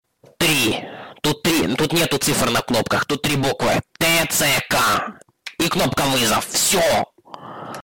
ttsk Meme Sound Effect
Category: Meme Soundboard